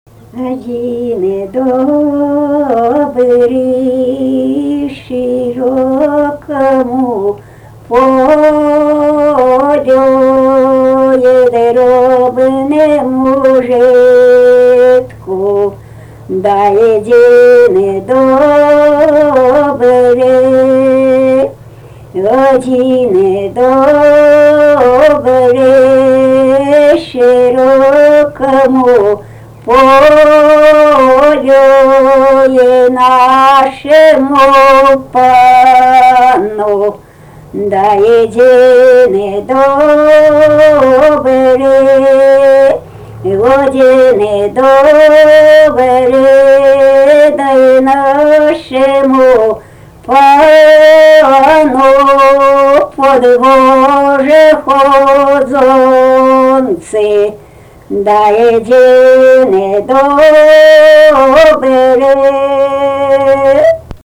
daina, kalendorinių apeigų ir darbo